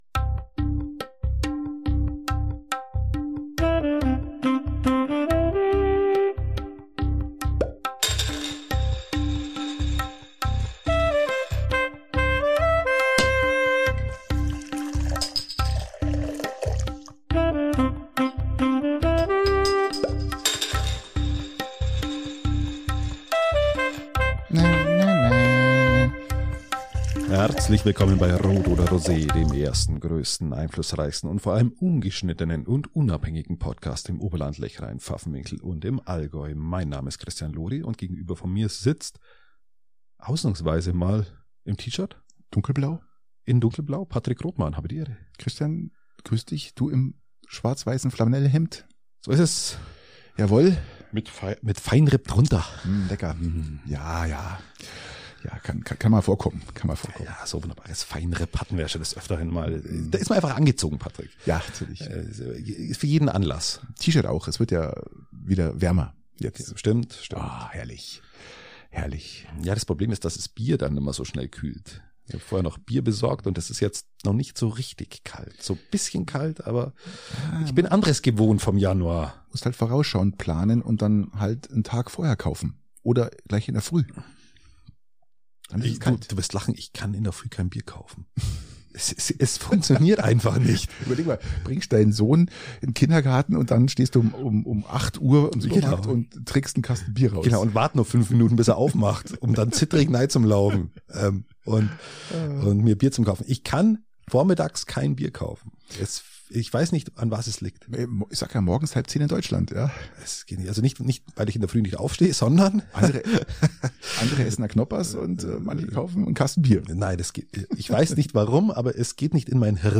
- Sagenedition - In dieser Episode erleben die beiden Podcaster eine Odyssee über den Parkplatz des SCR zum Verletzungspech des ECP, bis hin zur Personalnot im Peitinger Wellenfreibad.
Ungeschnittenen und unabhängig!